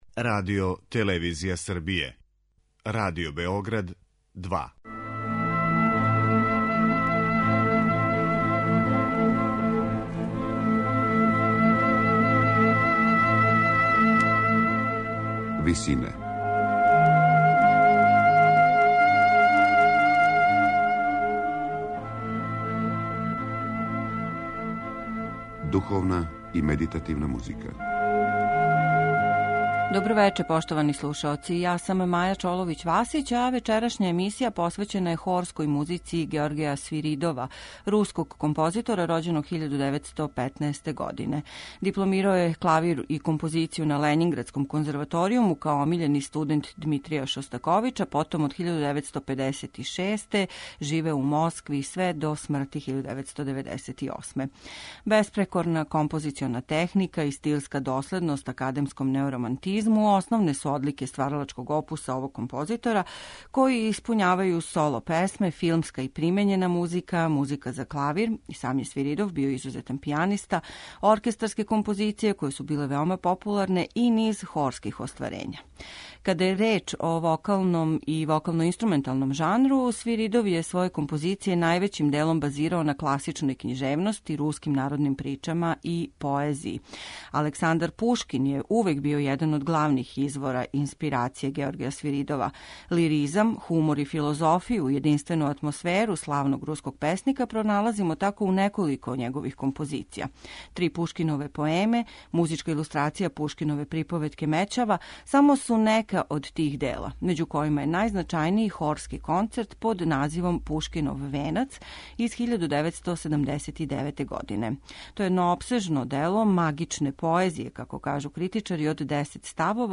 Хорски концерт